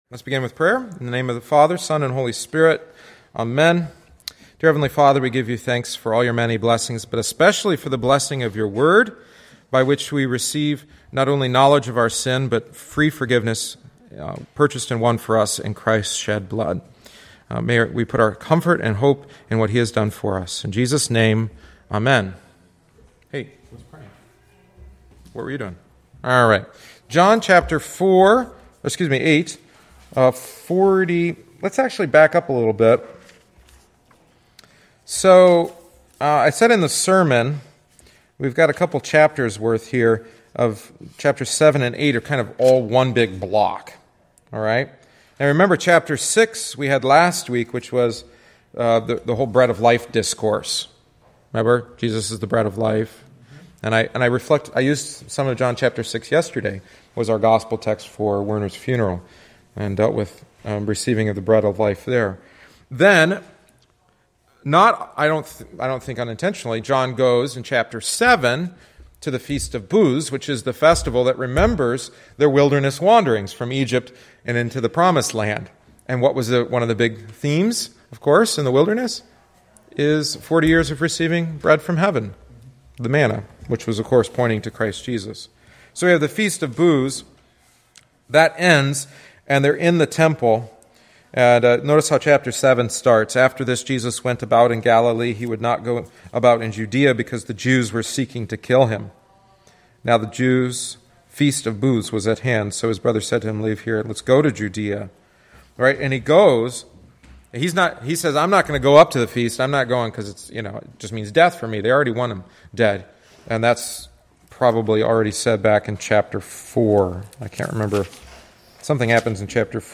Catechesis on John 8:46-59